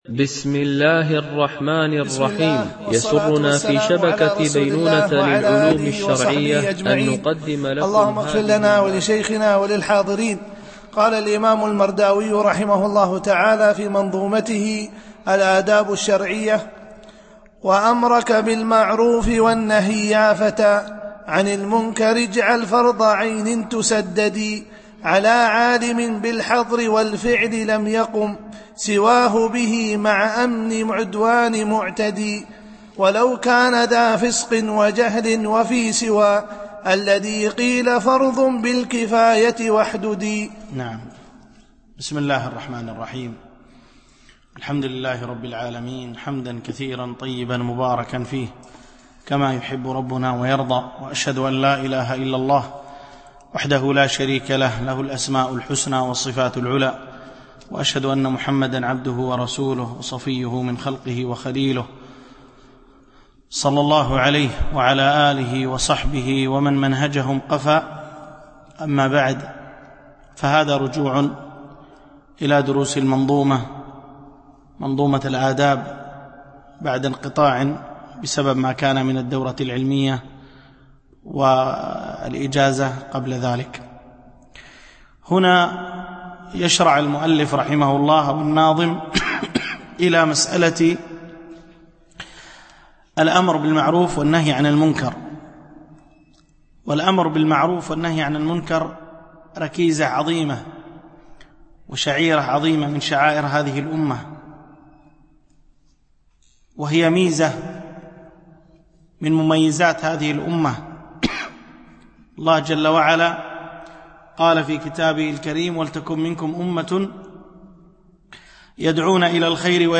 شرح منظومة الآداب الشرعية – الدرس 4 ( الأبيات 44 - 54)